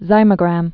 (zīmə-grăm)